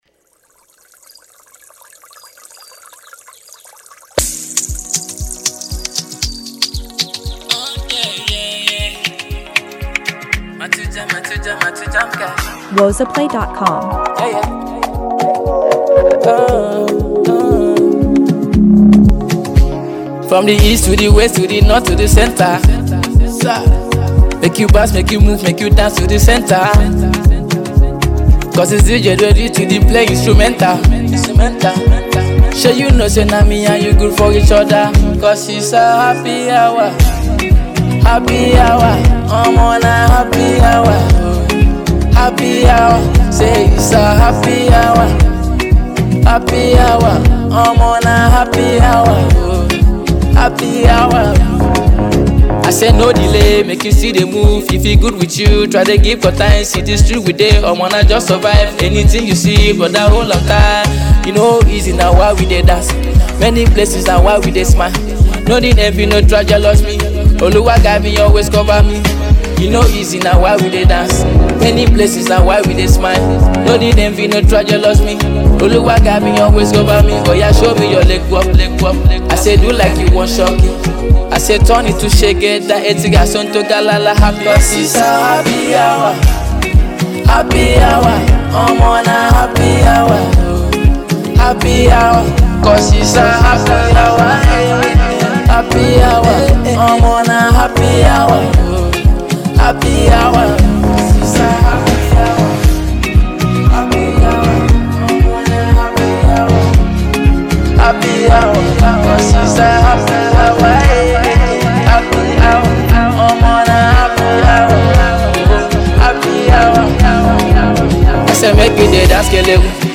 infectious blend of upbeat rhythms and smooth melodies